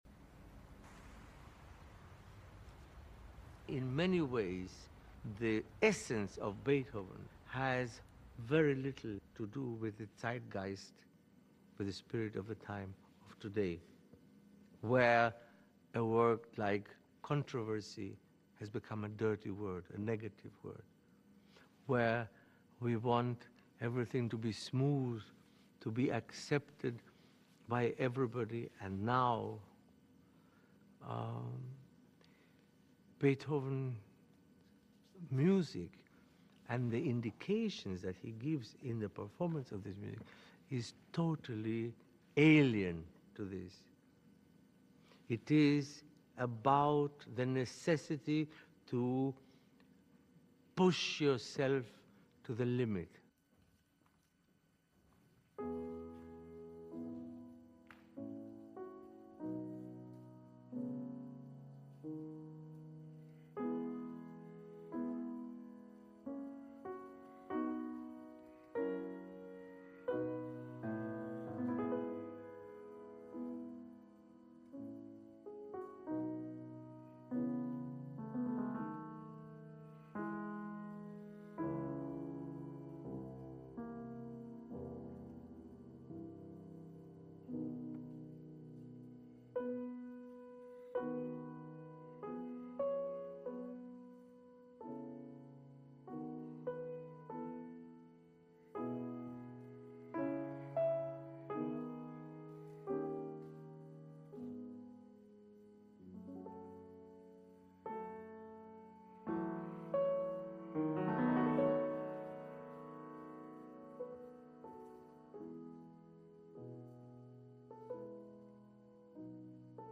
Tijdens die masterclass doet hij ook een aantal markante uitspraken.
uitspraak-barenboim.mp3